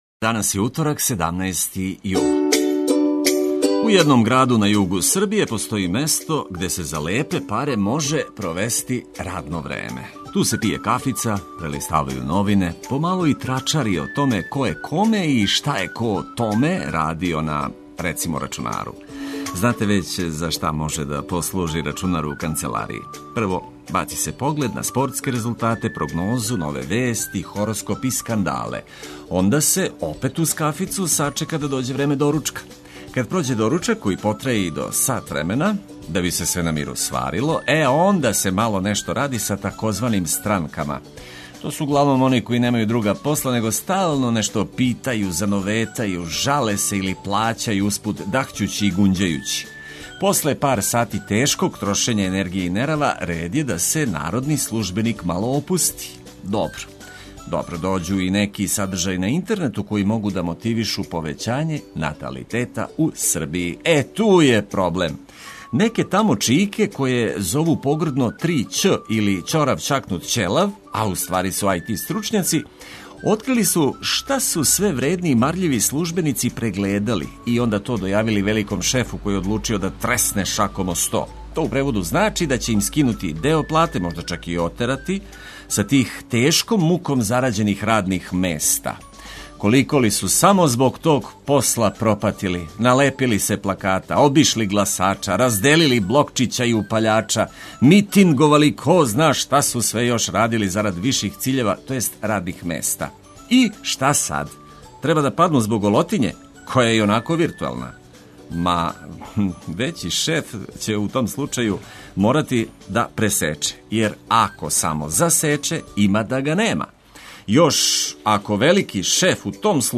Током емисије добра музика и корисне информације за лакши почетак дана.